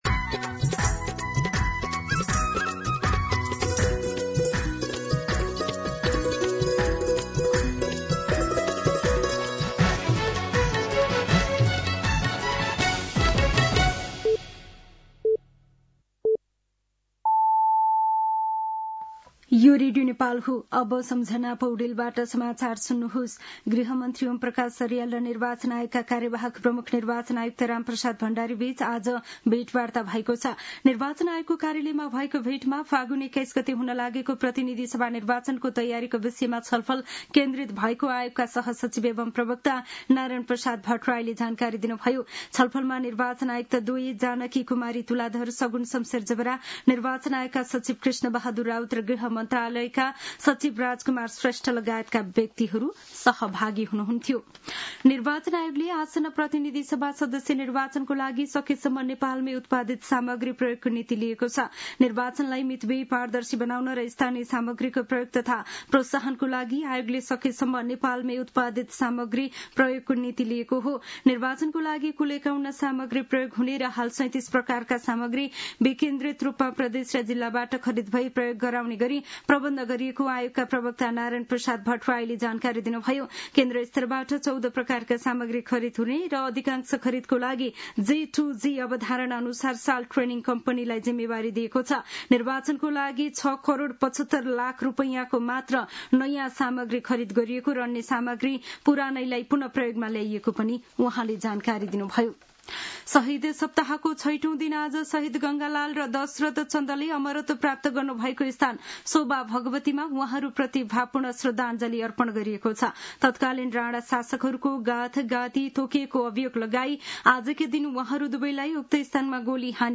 दिउँसो १ बजेको नेपाली समाचार : १५ माघ , २०८२